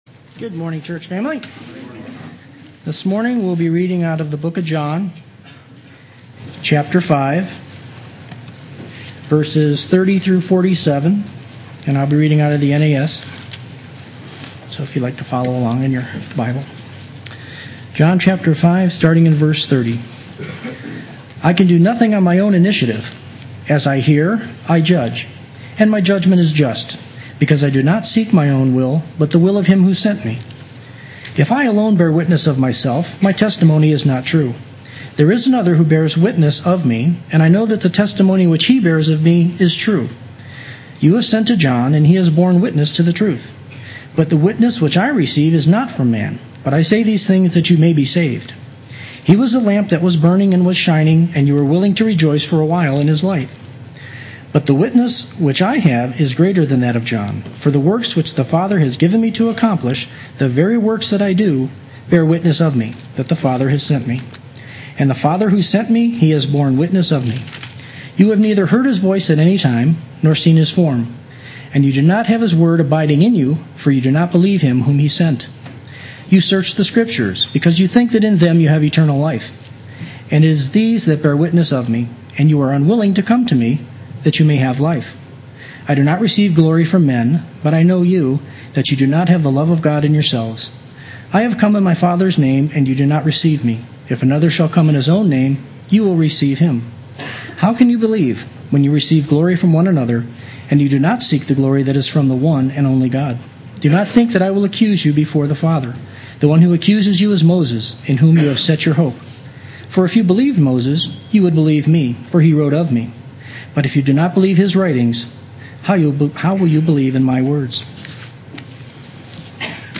Play Sermon Get HCF Teaching Automatically.
I Say These Things That You May Be Saved Sunday Worship